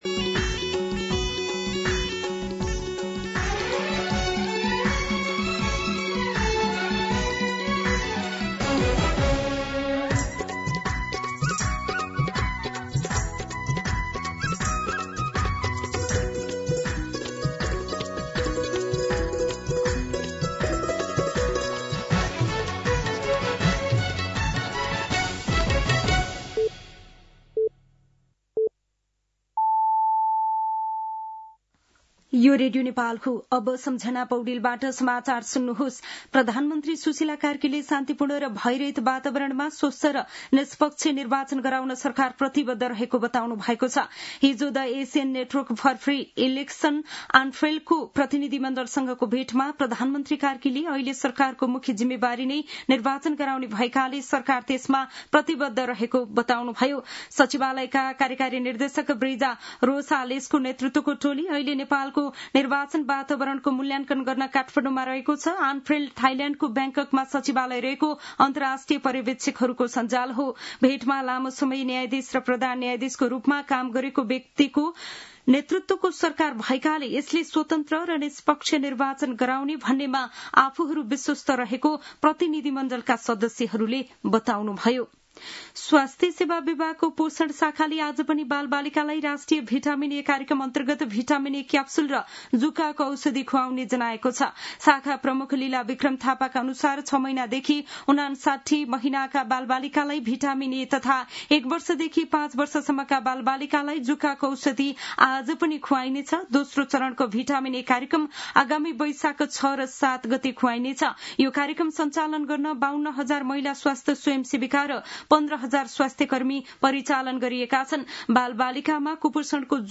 मध्यान्ह १२ बजेको नेपाली समाचार : २१ कार्तिक , २०८२